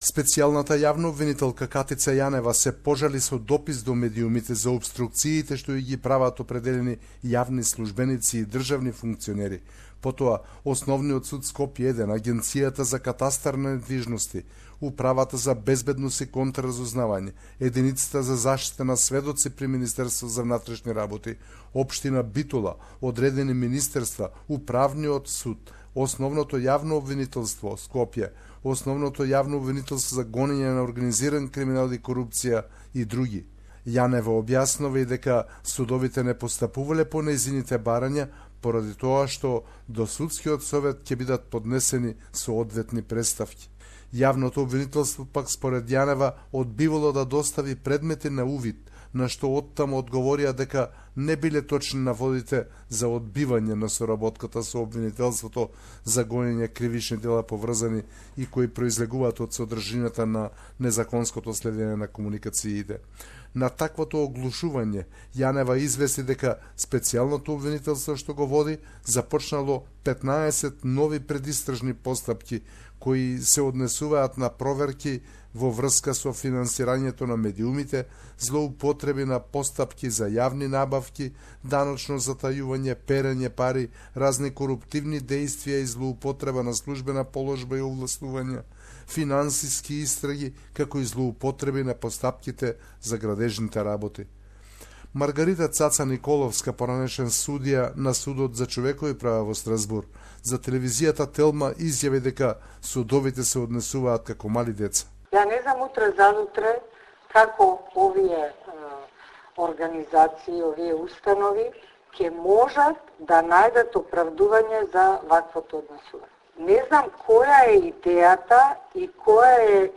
Macedonia Report